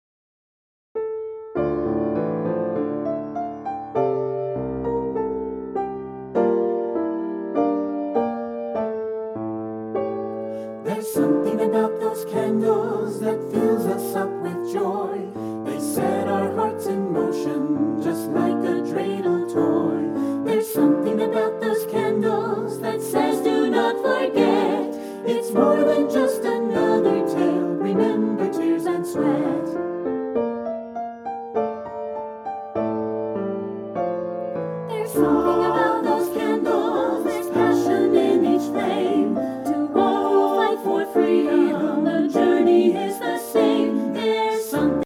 Listen to a sample of this song